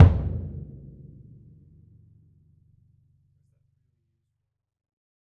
BDrumNewhit_v6_rr1_Sum.wav